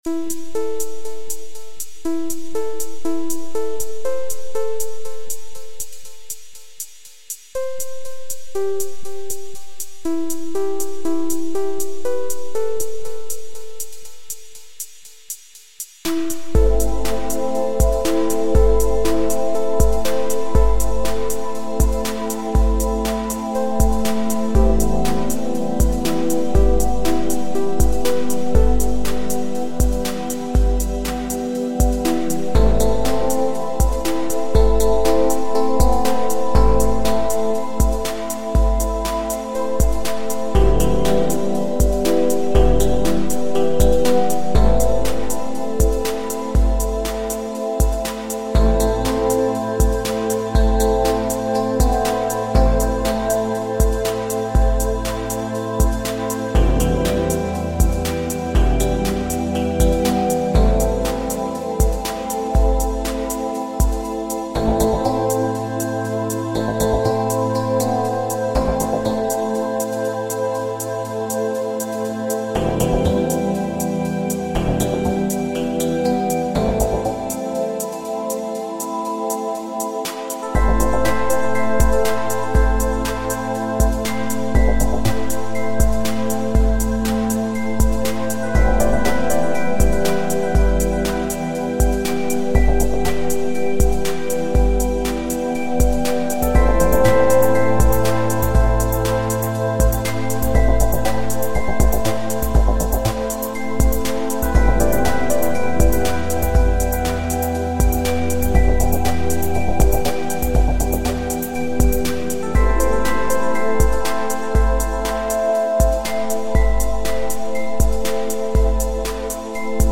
Žánr: Indie/Alternativa